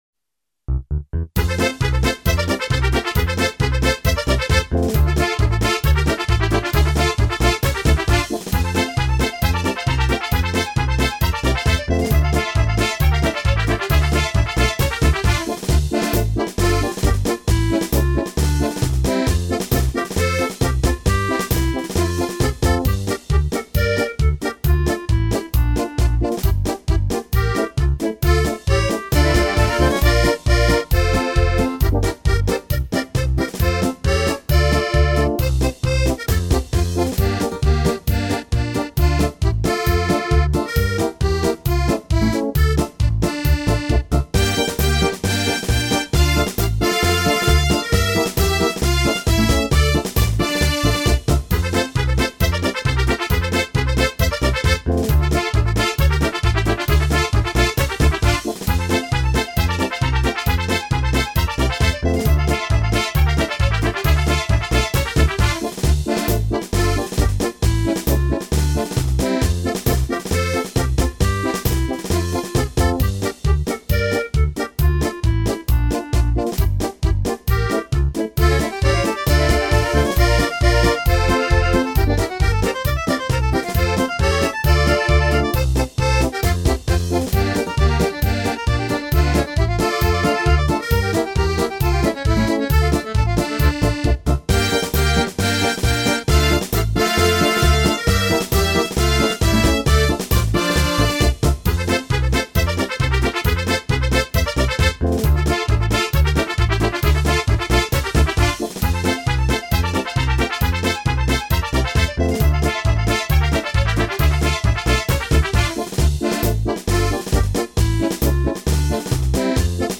Жартівливі пісні